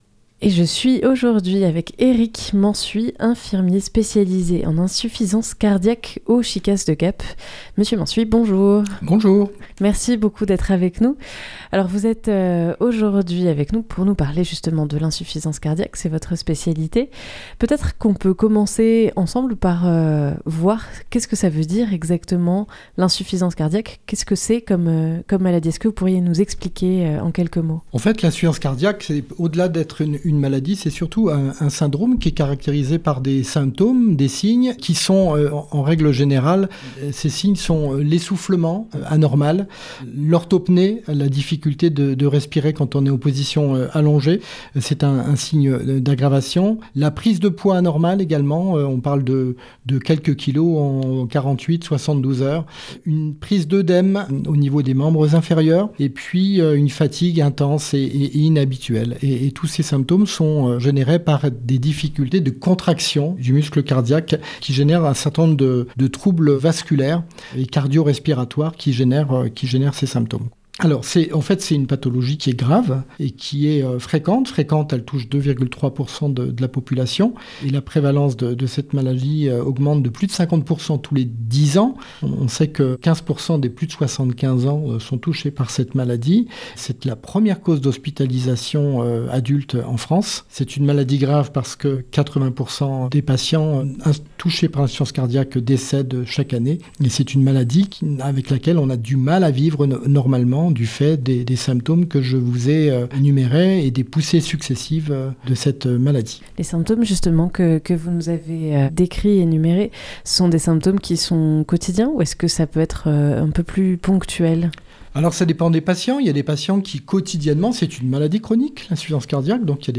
ITW